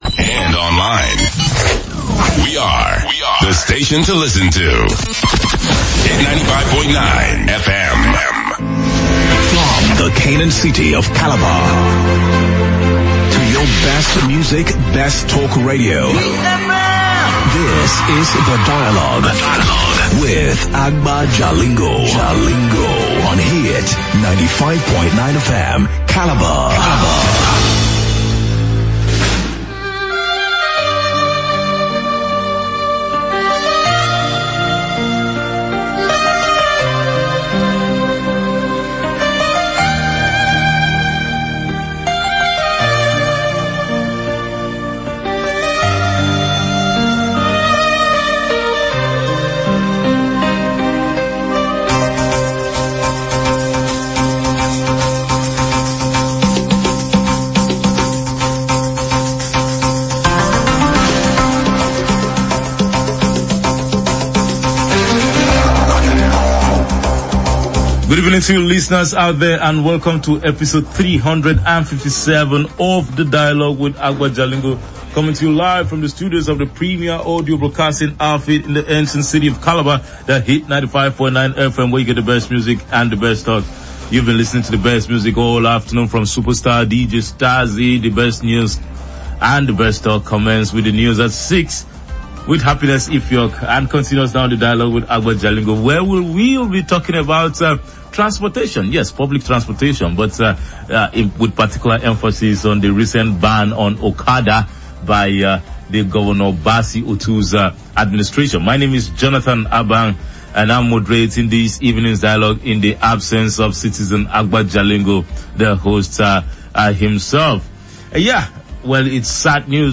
Tune in to Hit 95.9 FM every Sunday from 6:15PM – 7PM, for the live program.